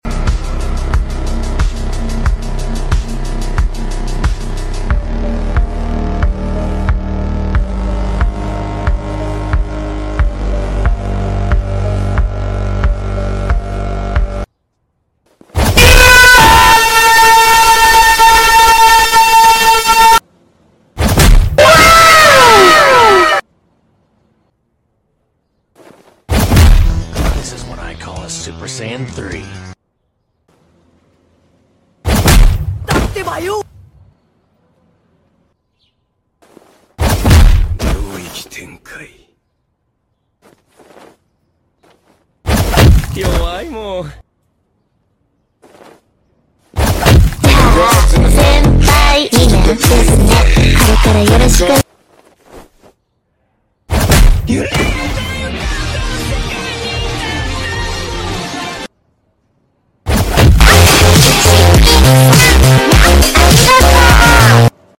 Anime kill sounds for TSB sound effects free download